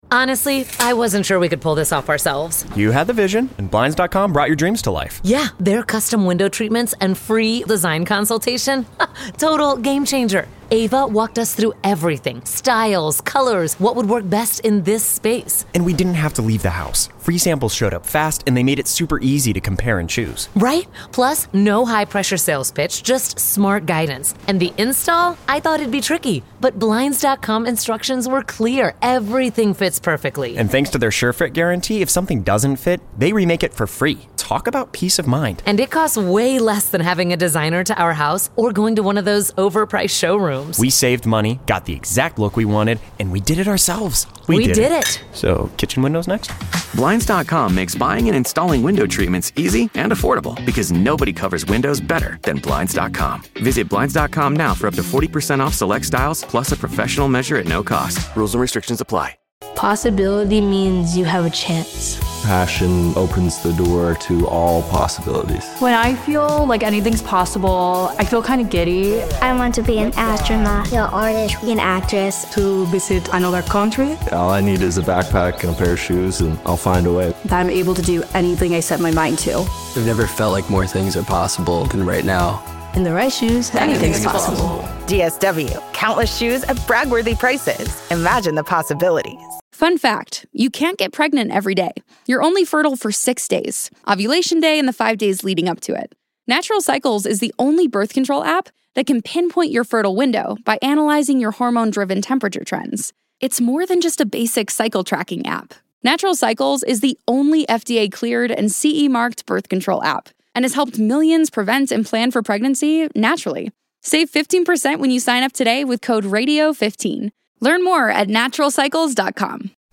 interviews the top country music artists on his tour bus.